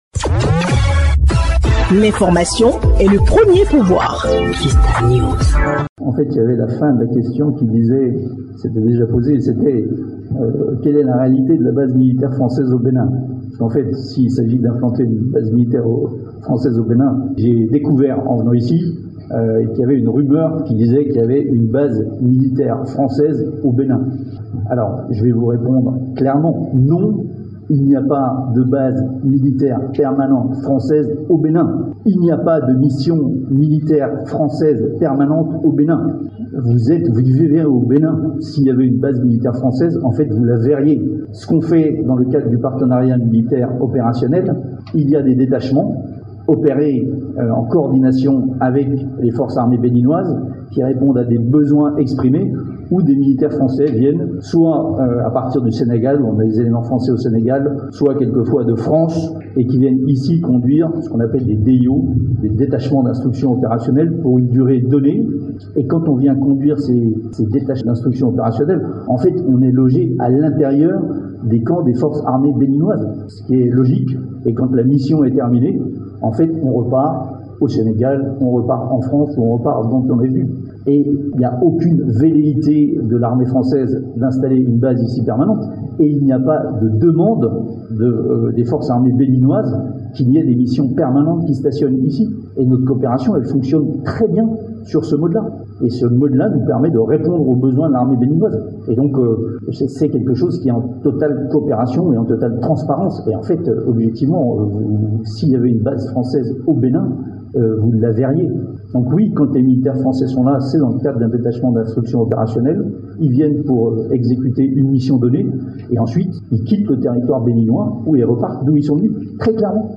Au détour d’une conférence de presse animée ce samedi 09 décembre 2023 à l’état-major général des armées à Cotonou, le chef d’état-major général de l’armée française en visite au Bénin et son homologue béninois ont répondu à une question des journalistes sur le sujet.
Voici ce que répondent successivement le général Thierry Burkhard de France et son homologue béninois le général Fructueux Gbaguidi.